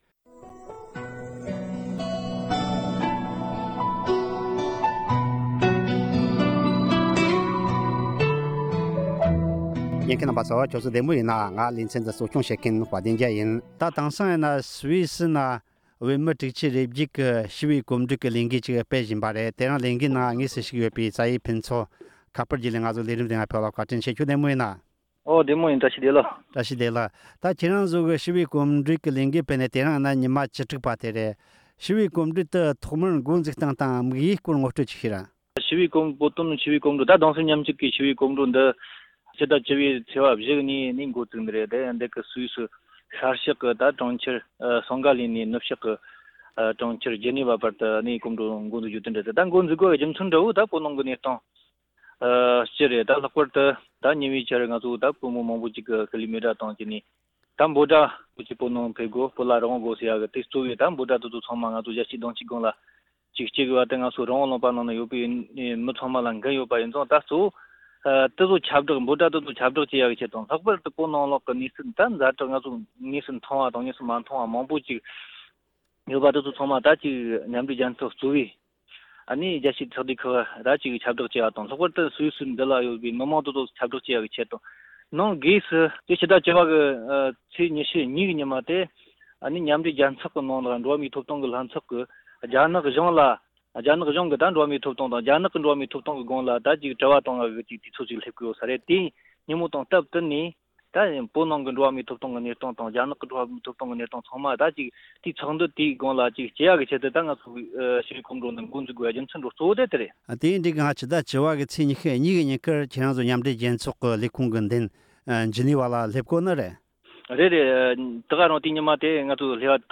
གོམ་བགྲོད་ནང་དངོས་སུ་ཞུགས་ཡོད་པའི་མི་སྣ་གཉིས་
གླེང་མོལ་